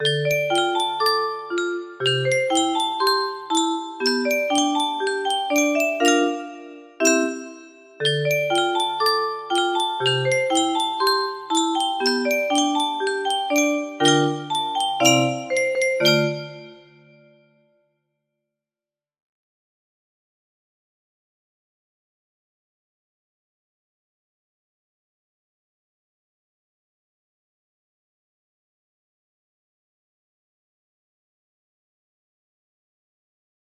music box melody
a really short piece, partly because it's the first i've ever made.